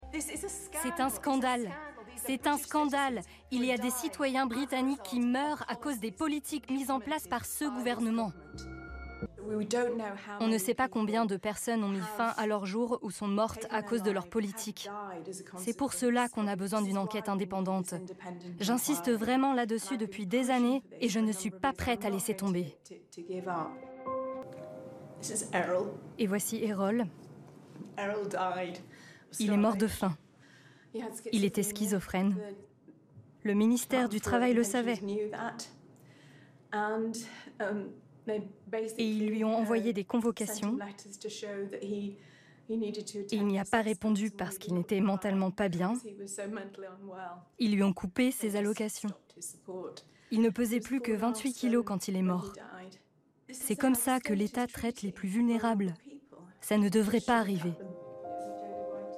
Bandes-son
Voice over - M6 enquete exclusive - français sur anglais